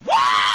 Worms speechbanks
jump1.wav